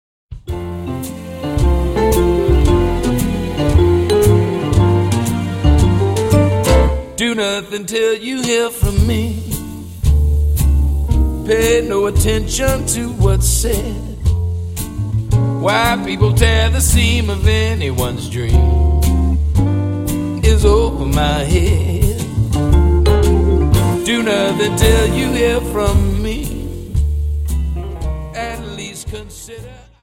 Dance: Slowfox 28 Song